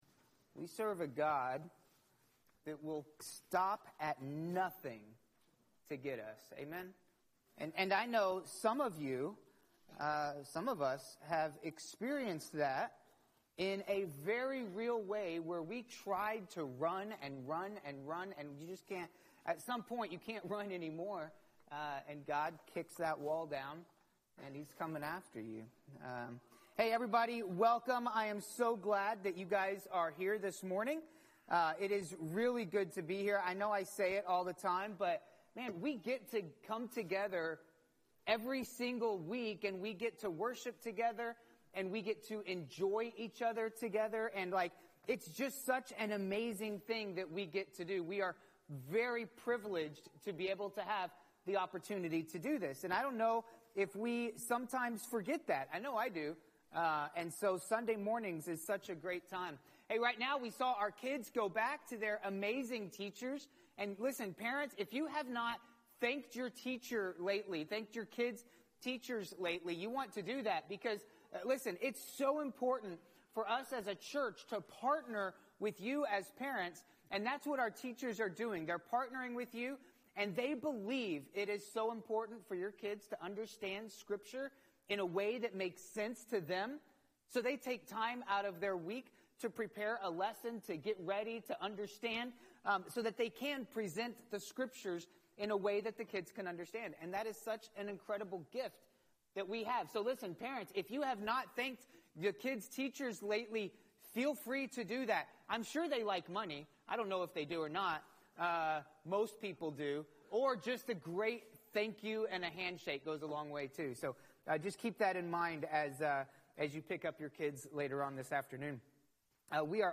Messages | Leaf River Baptist Church